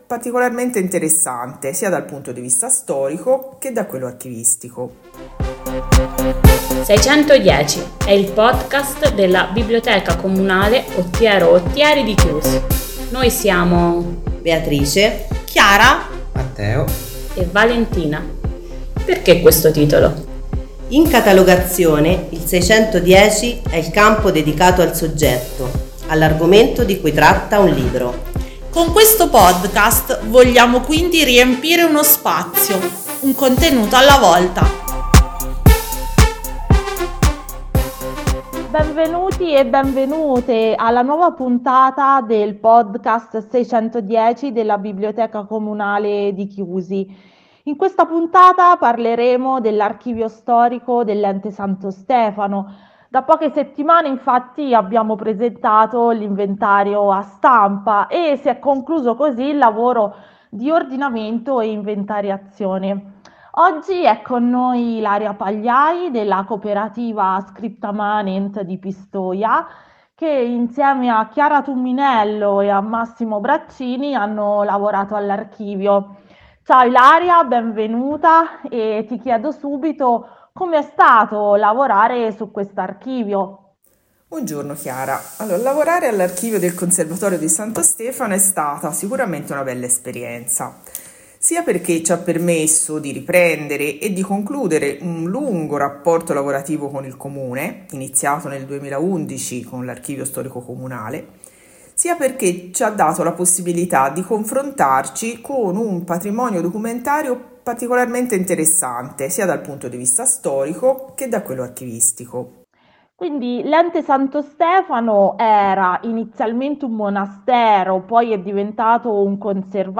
Il lavoro dell'archivista - intervista